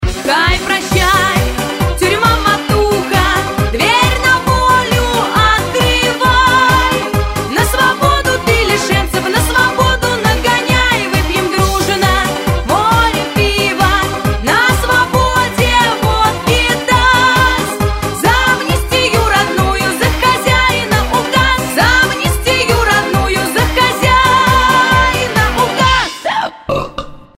• Качество: 128, Stereo
блатные